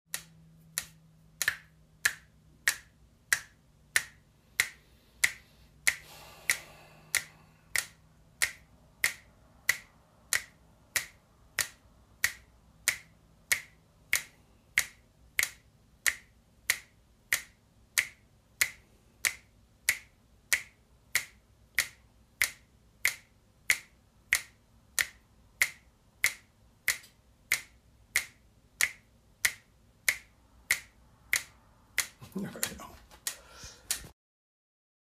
metronome
Snapsz.mp3